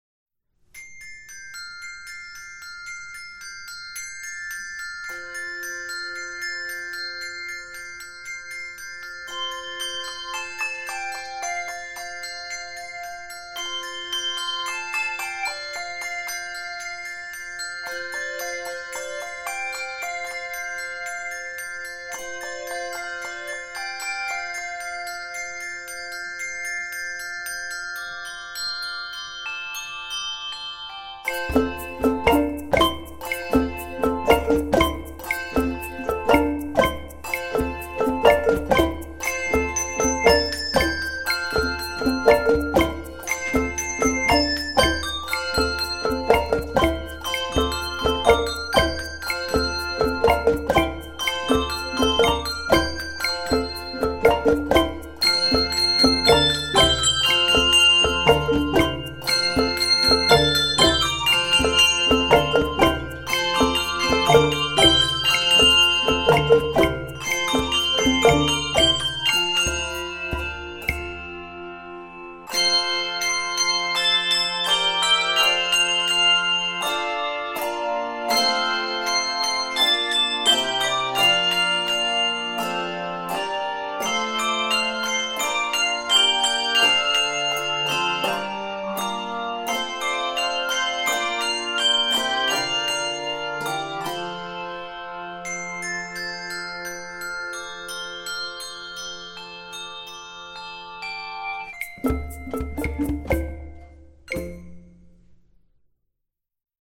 joyous setting
call and response